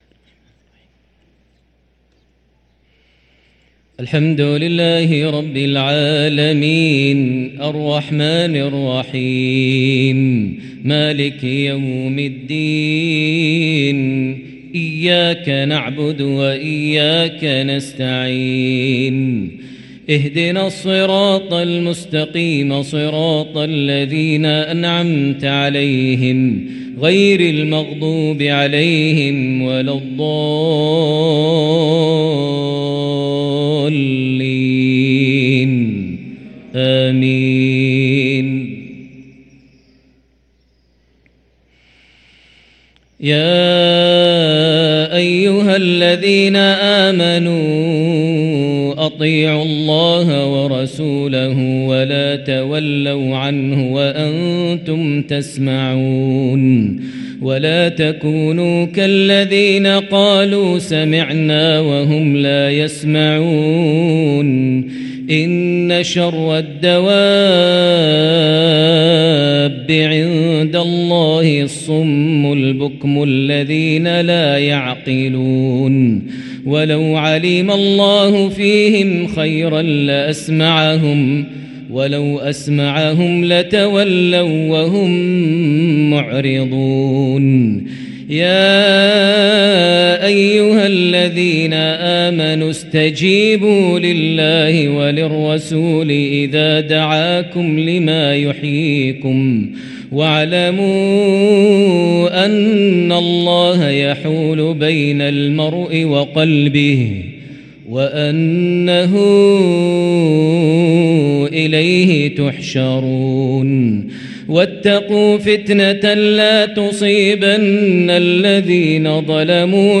صلاة المغرب للقارئ ماهر المعيقلي 28 رجب 1444 هـ
تِلَاوَات الْحَرَمَيْن .